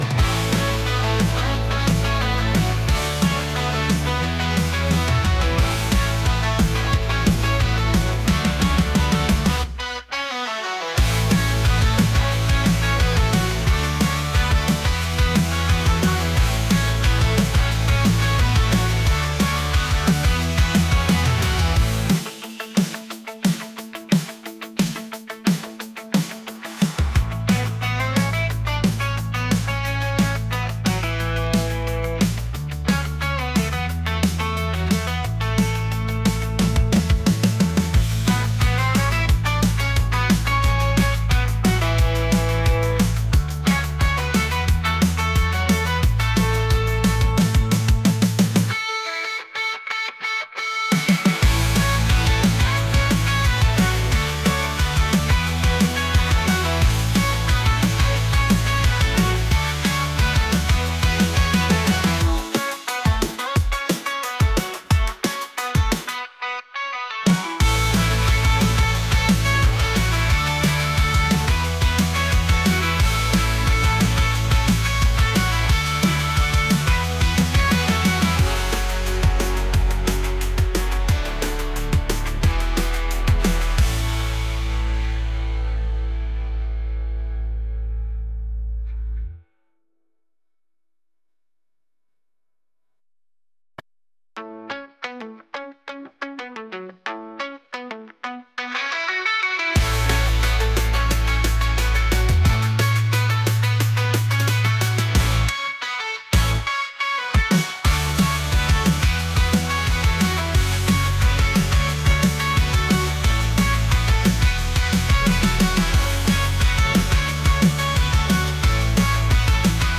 punk | rock